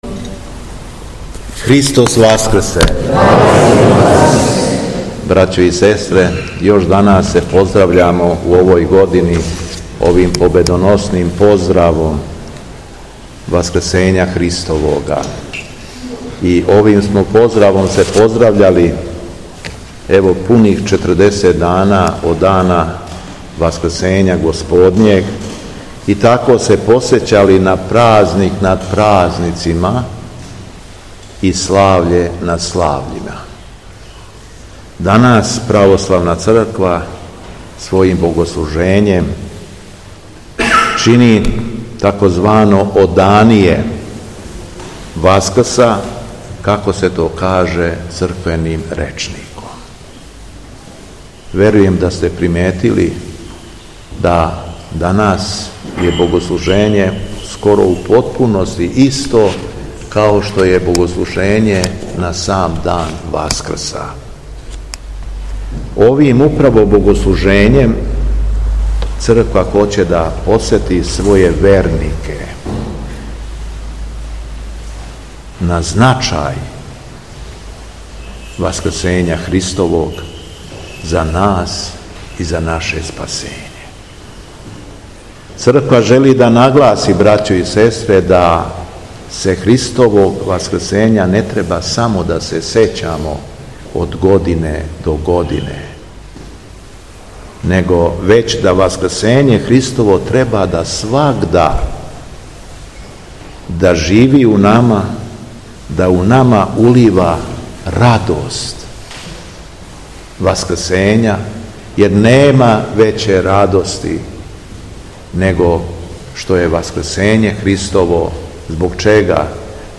Беседа Његовог Високопреосвештенства Митрополита шумадијског г. Јована
Након прочитане јеванђелске перикопе, Митрополит шумадијски Јован је произнео беседу, рекавши: